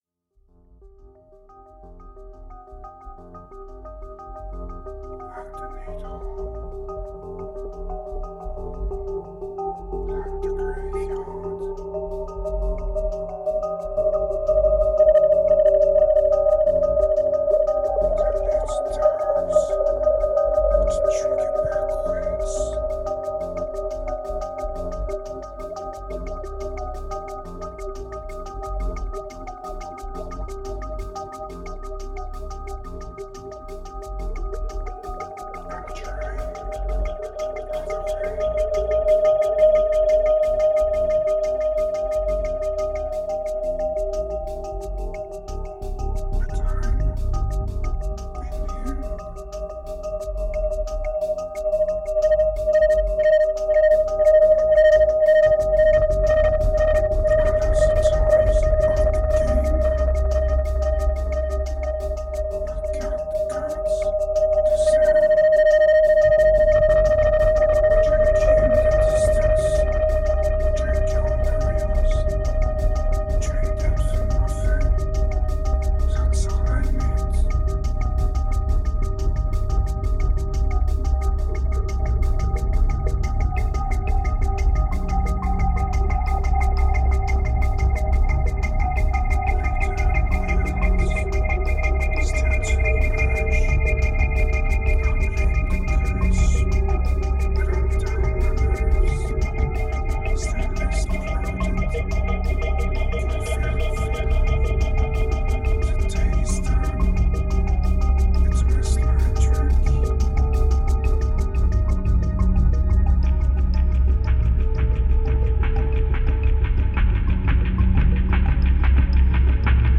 2139📈 - 33%🤔 - 89BPM🔊 - 2011-03-21📅 - -35🌟